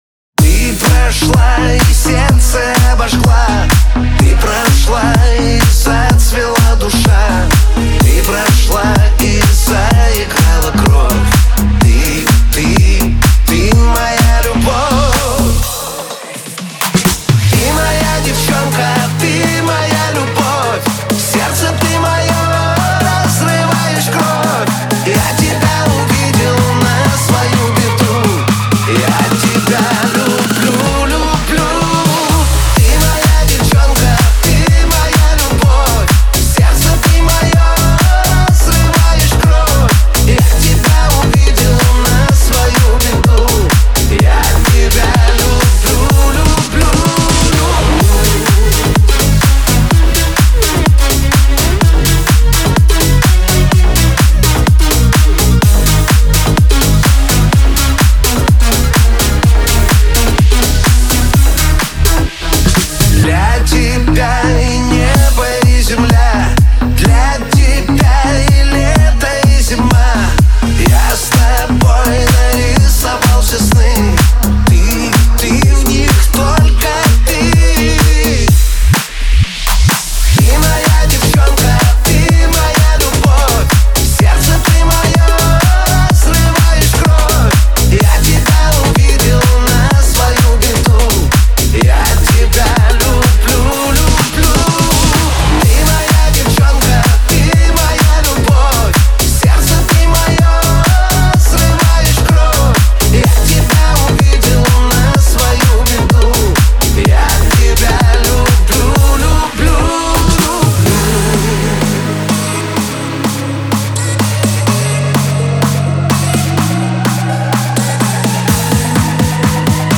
в этом треке сочетает мелодичность и ритмичность